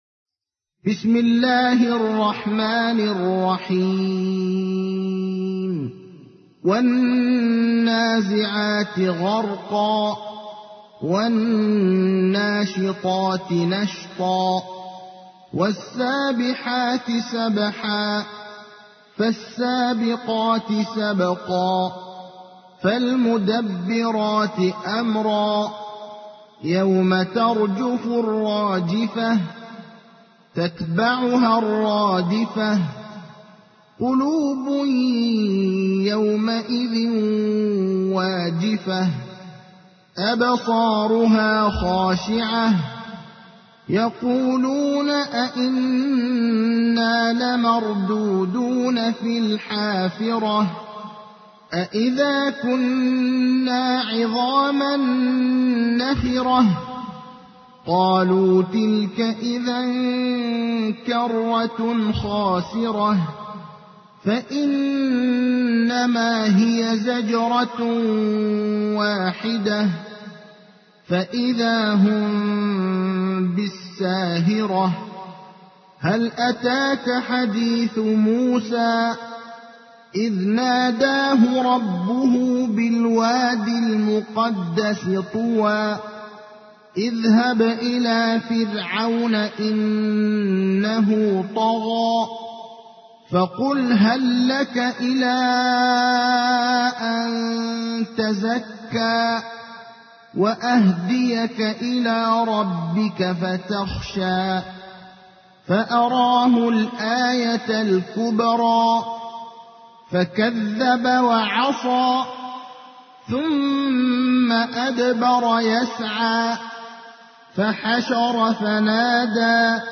تحميل : 79. سورة النازعات / القارئ ابراهيم الأخضر / القرآن الكريم / موقع يا حسين